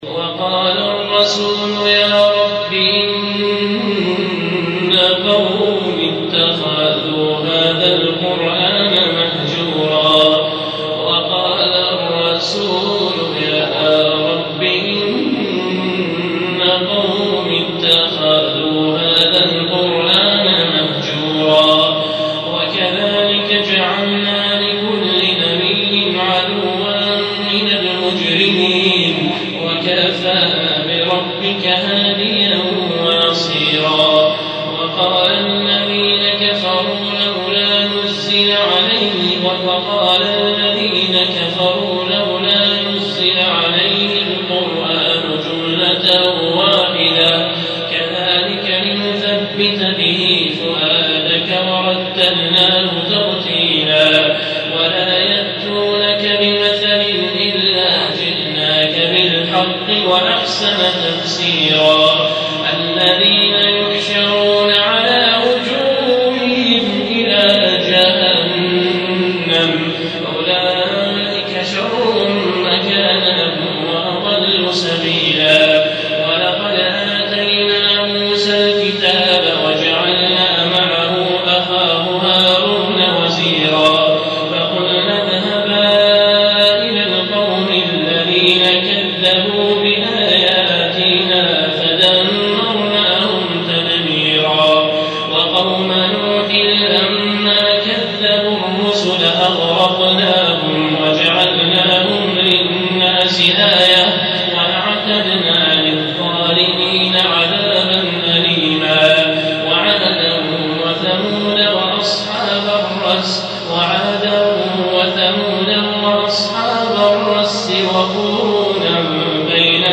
هذا تسجيل من صلاة التراويح وصوته يهبل